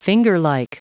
Prononciation du mot fingerlike en anglais (fichier audio)
Prononciation du mot : fingerlike